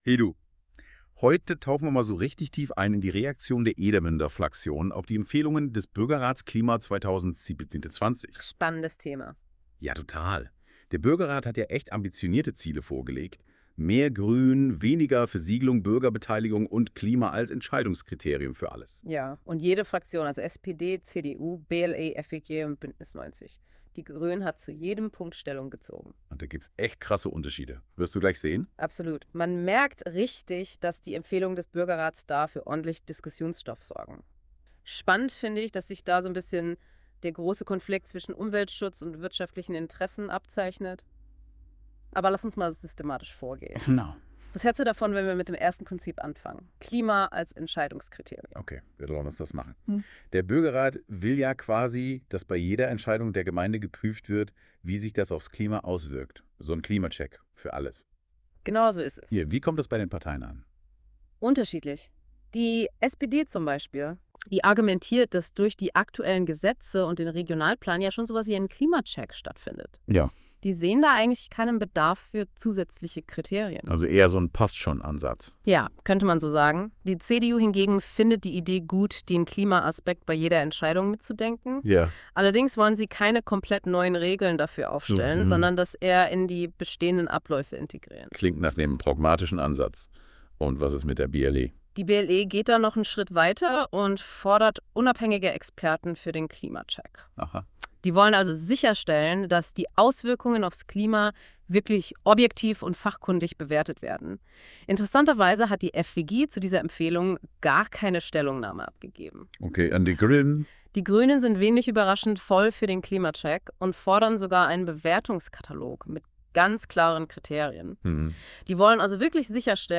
Podcast Edermuende Buergerrat Klima 2024 Fraktionen Stellungnahmen und Empfehlungen Hinweis: Dieser Podcast wurde mit Hilfe einer künstlichen Intelligenz erzeugt.